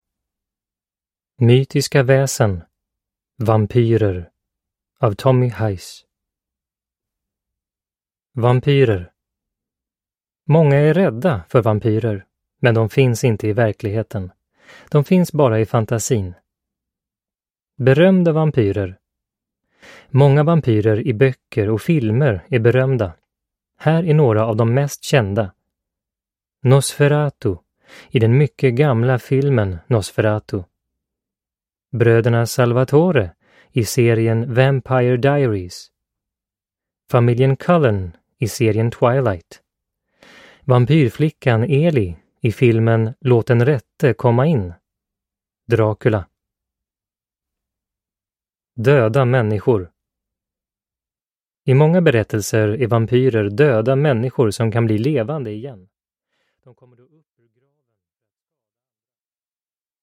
Mytiska väsen - Vampyrer (ljudbok